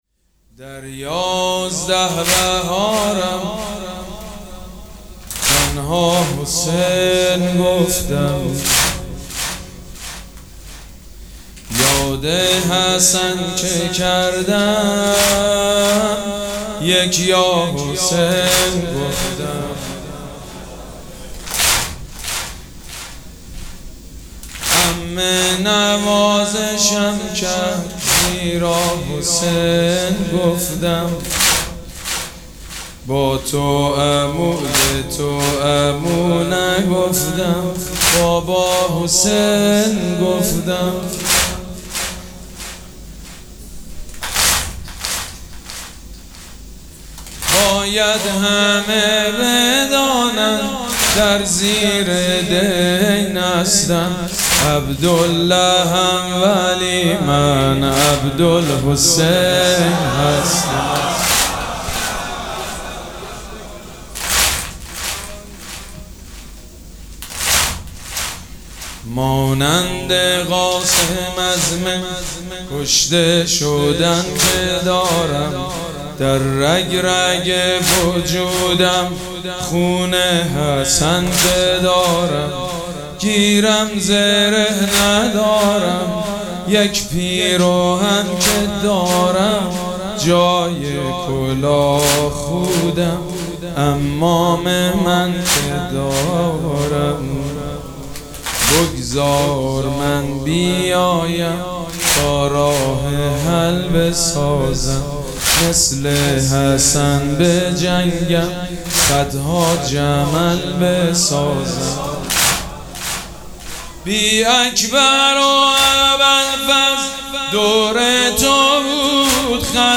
مراسم عزاداری شب پنجم محرم الحرام ۱۴۴۷
حاج سید مجید بنی فاطمه